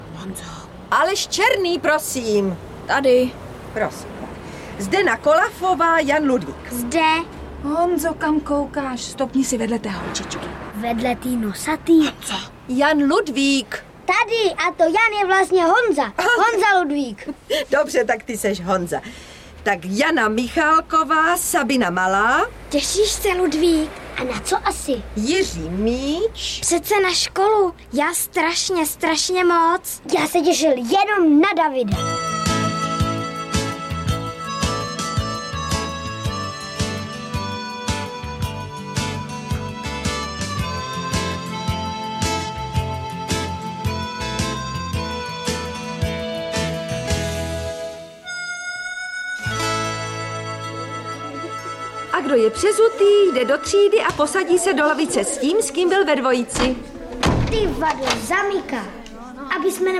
Audiobook
Audiobooks » Short Stories
Read: Růžena Merunková